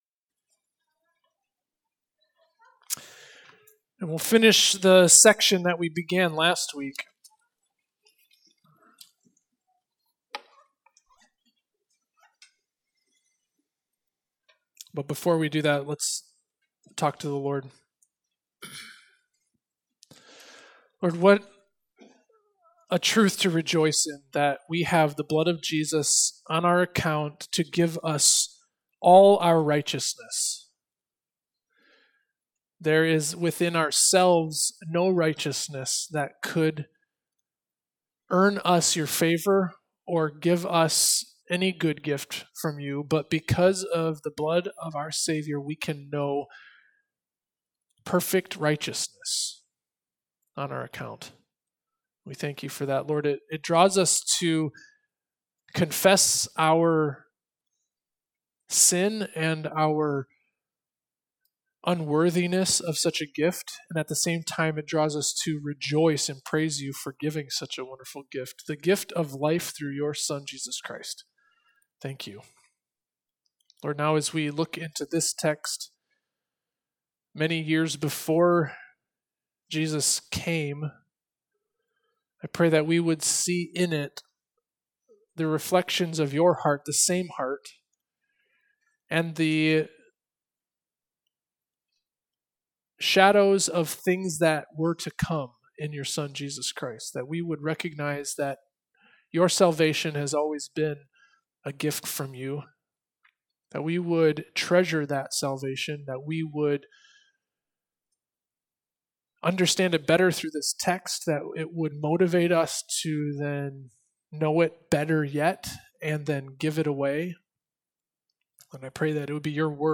Sermons :: Faith Baptist Church